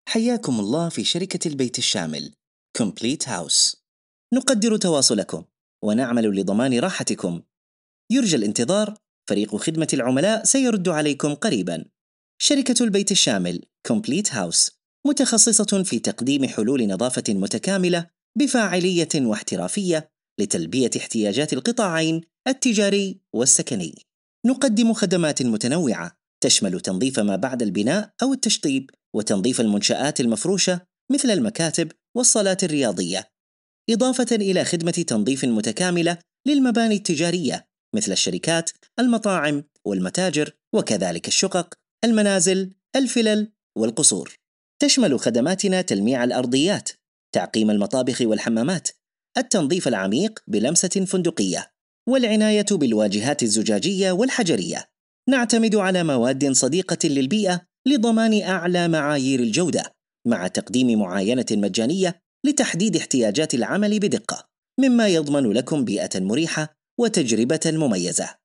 تعليق صوتي | شركة نسق للدعاية والتسويق
Completehouse_IVR.mp3